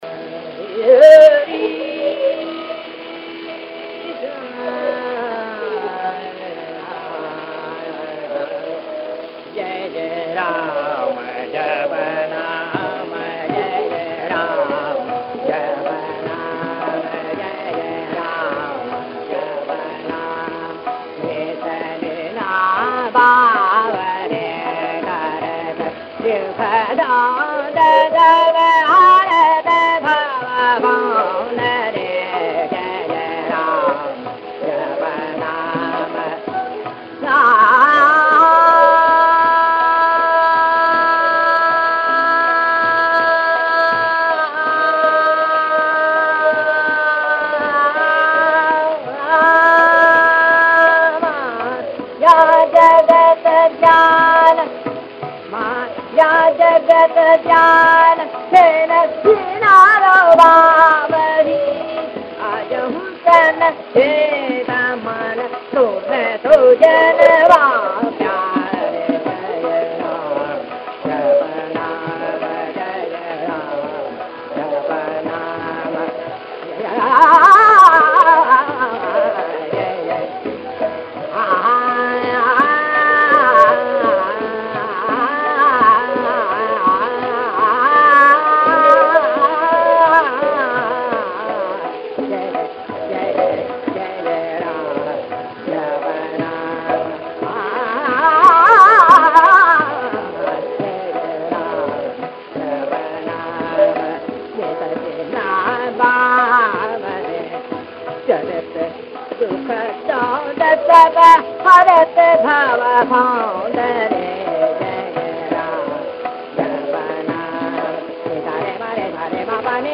उनके कुछ ही ऑडियो क्लिप विनाइल डिस्क या रेडियो कार्यक्रमों में रिकॉर्ड किए गए हैं, और संभवतः 1940 या 1950 के दशक में वे रिकॉर्ड किए गए थे।